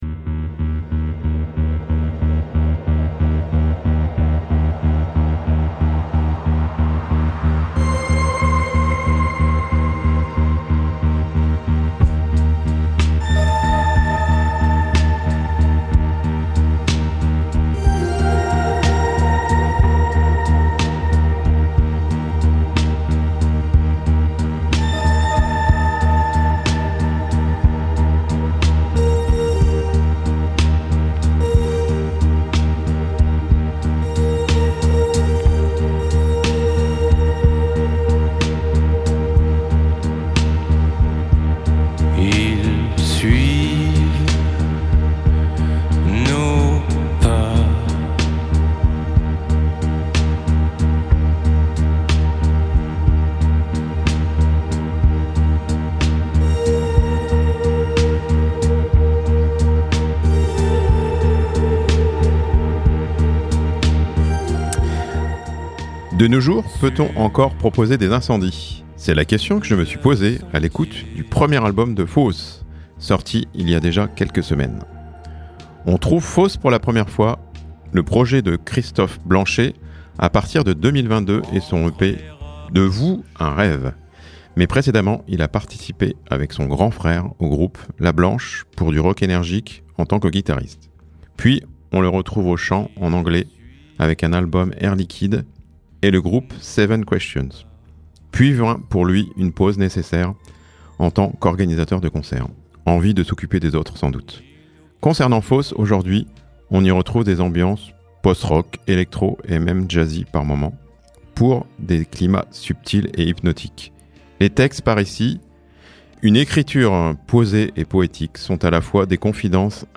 Chronique du 13 mai (7:48)